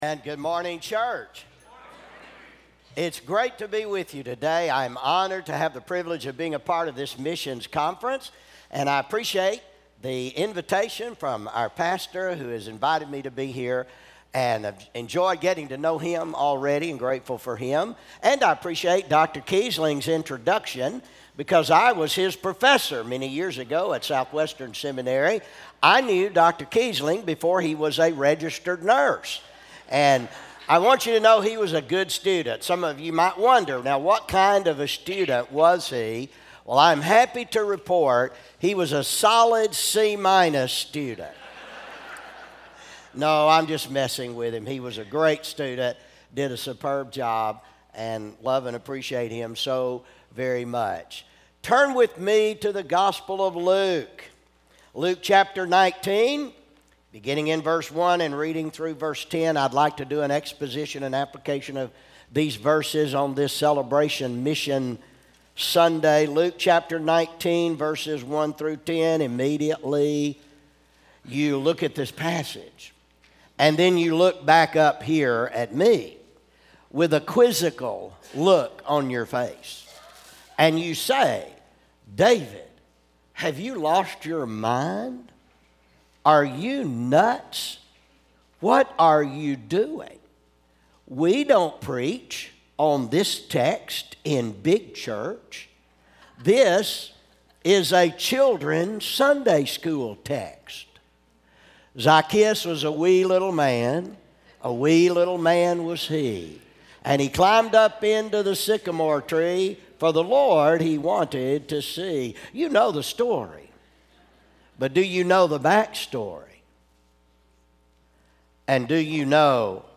Sermons - First Baptist Church O'Fallon